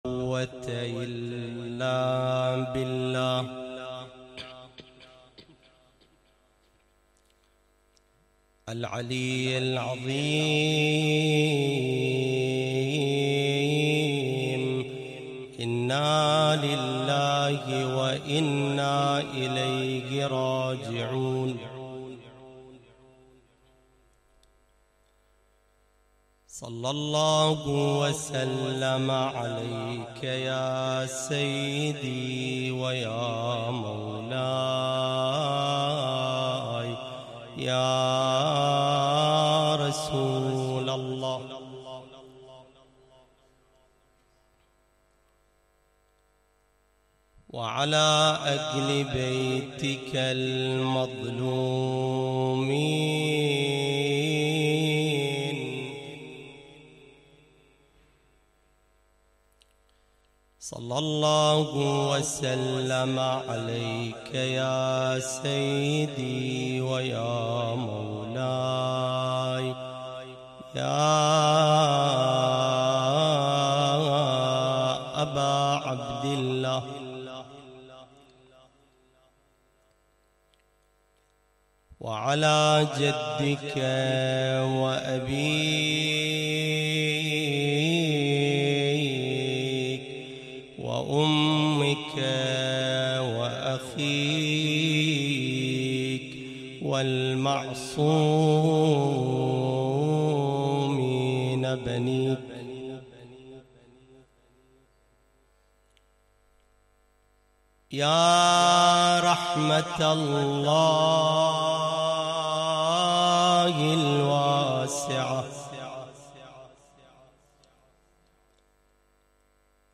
تغطية شاملة: المجلس الحسيني ليلة 14 رمضان 1440هـ
محاضرة ليلة 14 رمضان 1440 – ليلة الاثنين 19 مايو 2019 امتحانات عصر الغيبة تغطية صوتية